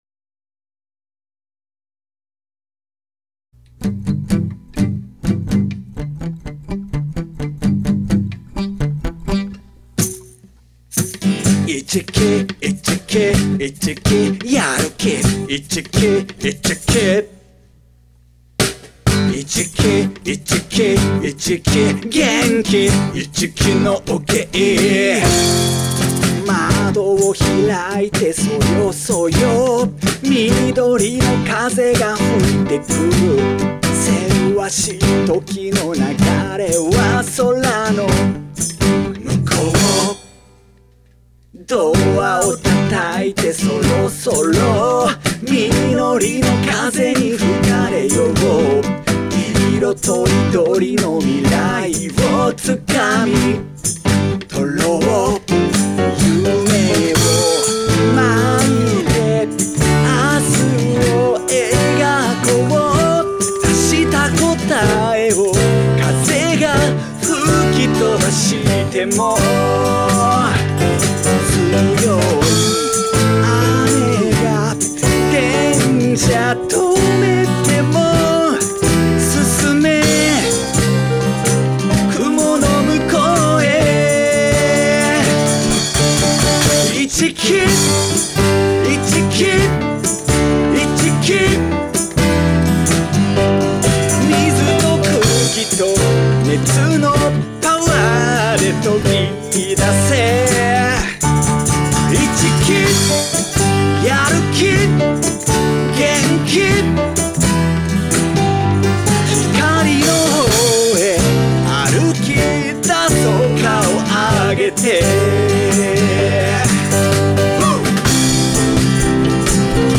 市来農芸高校 応援ソング（音源・歌詞あり） | 鹿児島県立
演奏も先生方が行っています。